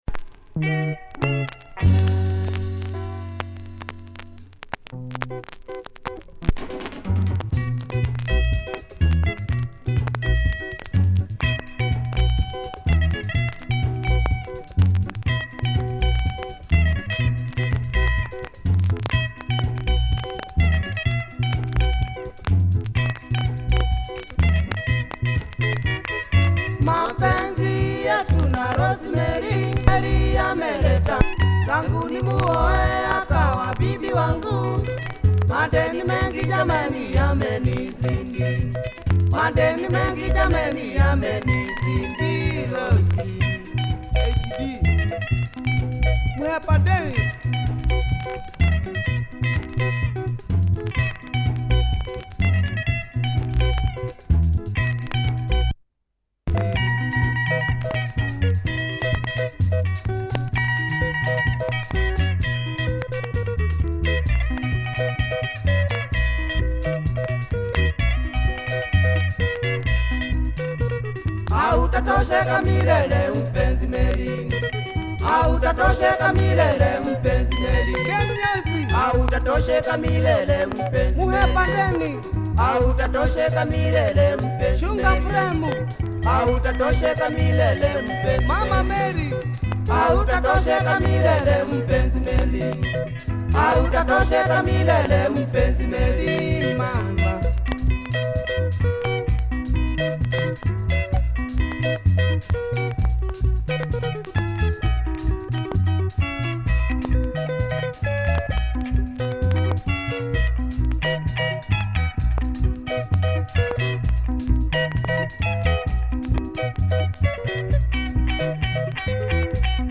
cool guitar lick! Disc has marks that affect play. https